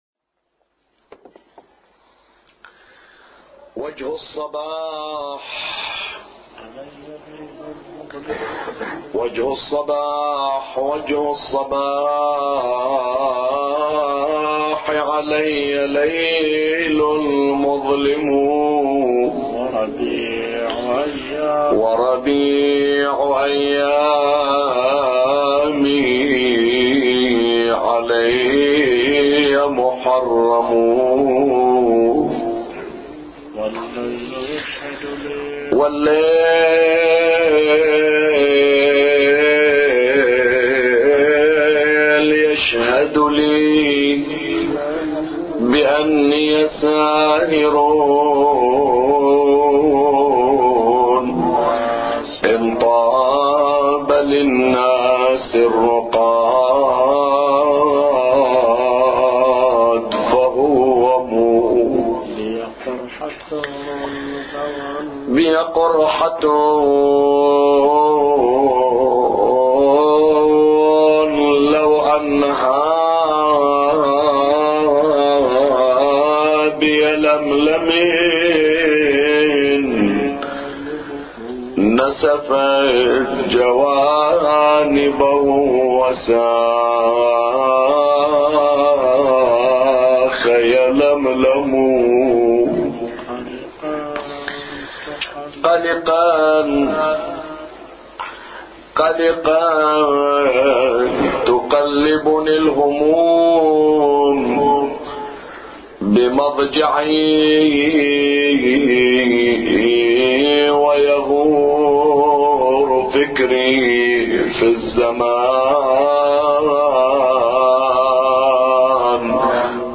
أبيات حسينية – ليلة الخامس من شهر محرم